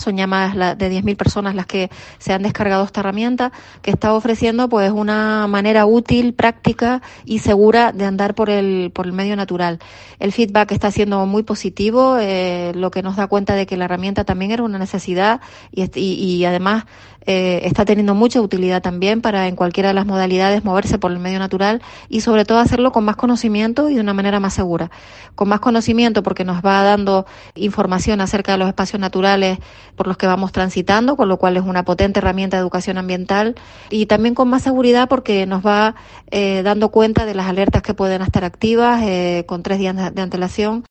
Isabel García, consejera de Medio Natural del Cabildo de Tenerife